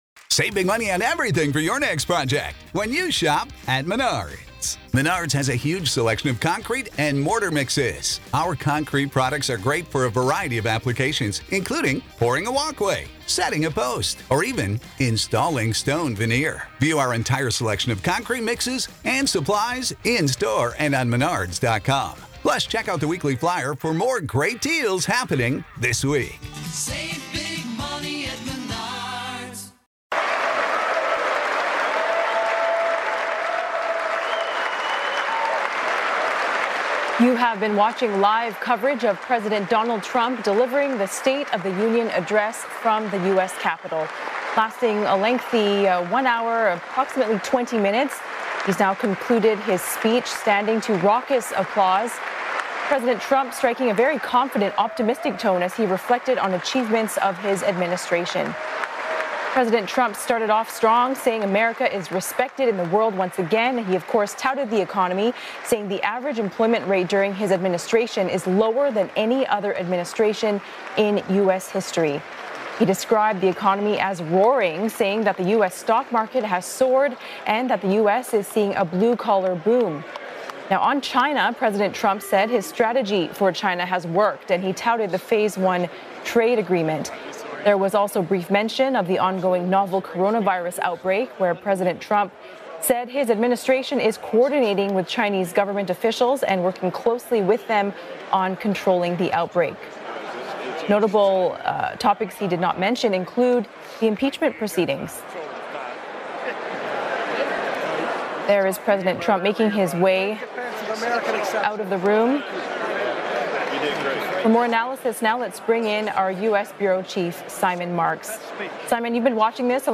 For pan-Asian TV network CNA.